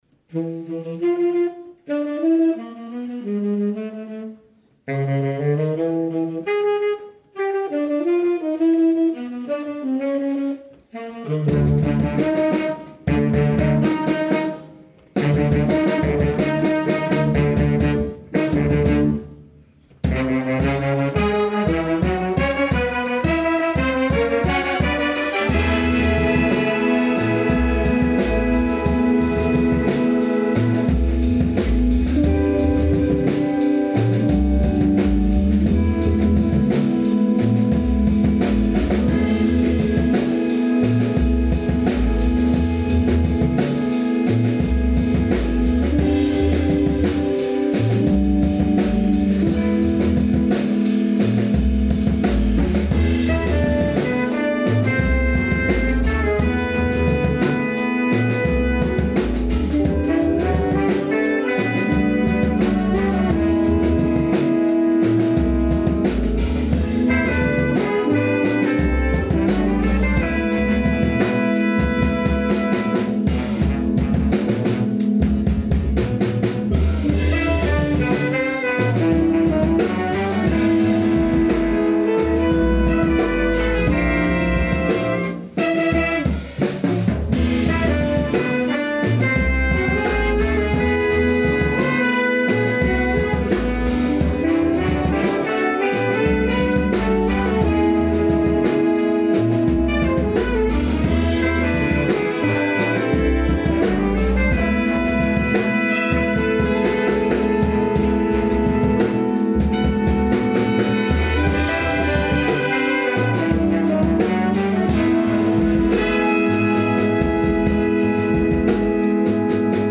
A funky, dirty Groovepiece in 5/4 and 4/4
a blasting Groove-Piece with electric bass and keyboards
Unisono-Stuff and a heroic Guitar-Solo.
5-4-4-Dr, El. Bass, Git, Keys